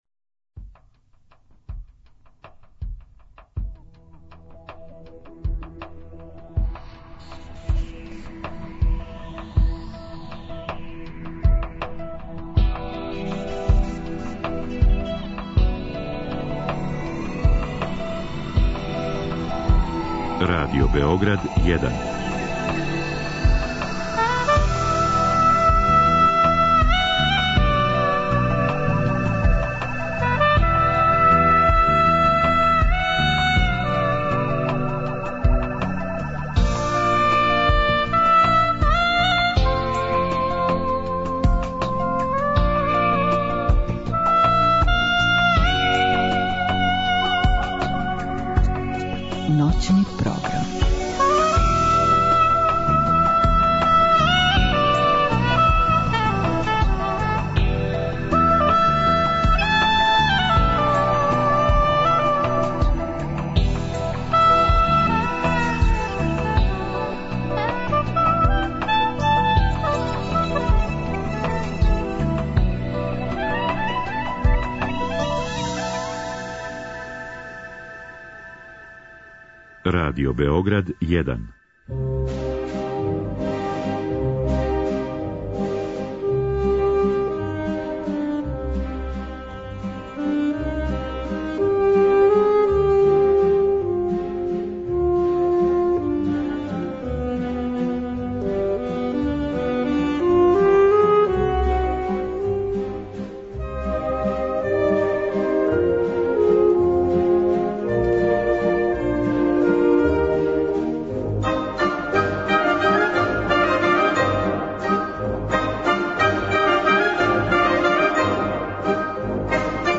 У знаку клавира биће и наставак Ноћног програма посвећеног уметничкој музици, па ћете, поред осталог, слушати Клавирски концерт бр.2 Сергеја Рахмањинова, Клавирски квинтет бр. 2 Антоњина Дворжака, одабране Прелиде Клода Дебисија и Голдберг варијације Јохана Себастијана Баха.